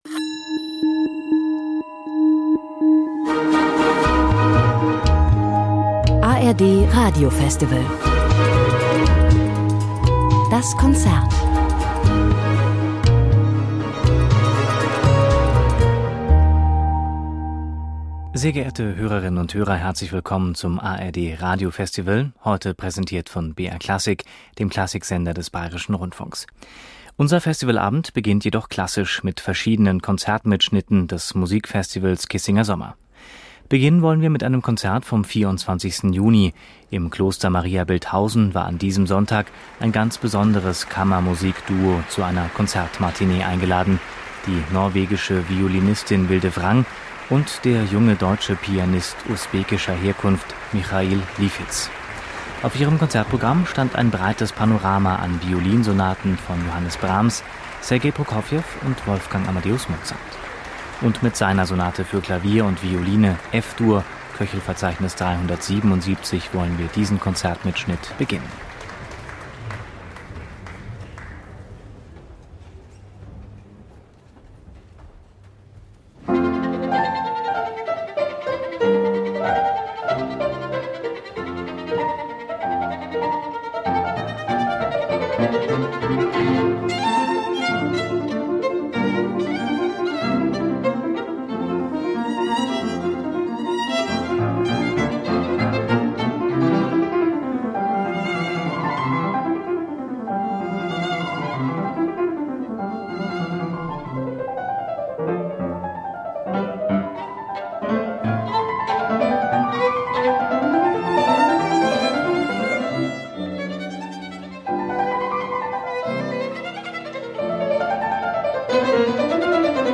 Over to Bad Kissinger in Germany this week for a concert recital from the Kissinger Sommer Festival by rising-young-star-wunderkind Vilde Frang in a program of music by Mozart, Brahms and Prokofiev. The concert was recorded on June 24th for the ARD Radio Festival 2012 going on throughout Germany this month.
Vilde Frang, Violine
Klavier Wolfgang Amadeus Mozart Violinsonate F-Dur KV 377 Johannes Brahms 3.